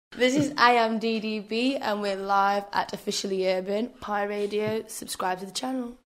アイアムディーディービー
（※アイム・ディーディービーと紹介されるケースもあるが、本人は「アイ・アム」と発音している。）
本人による自己紹介